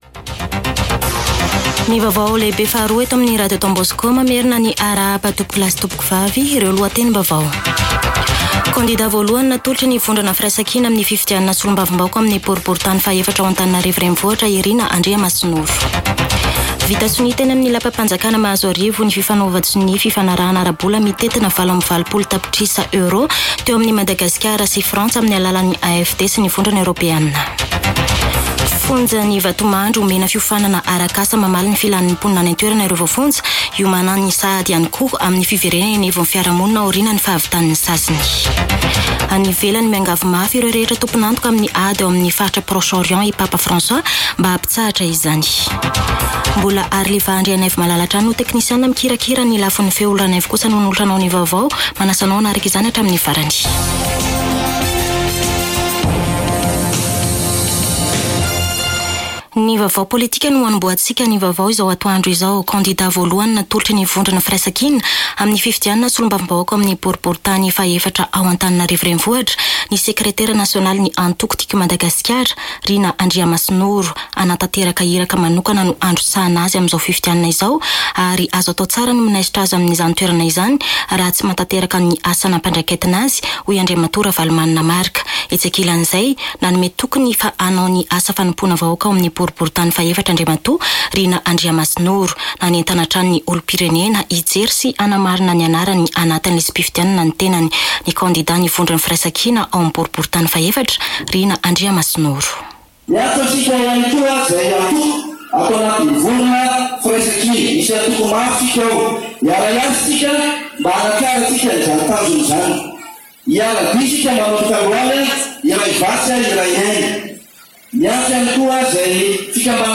[Vaovao antoandro] Alatsinainy 4 marsa 2024